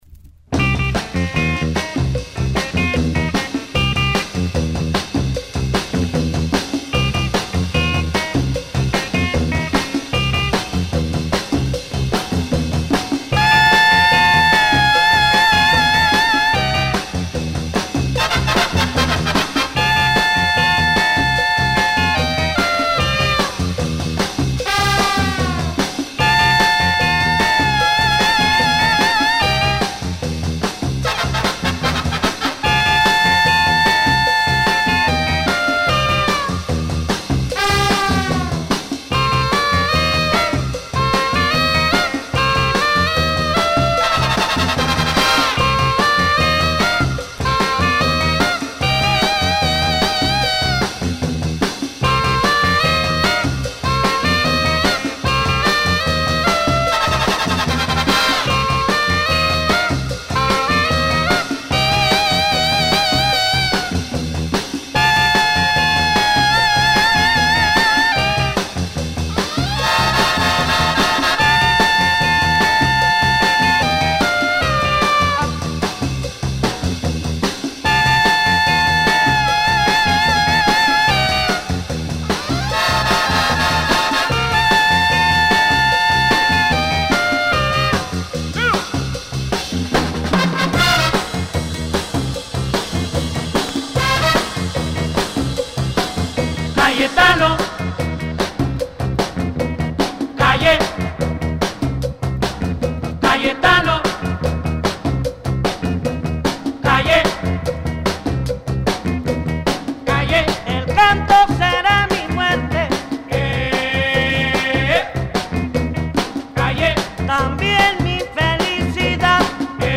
The A side is pure go-go music.